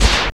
ZAPPER.wav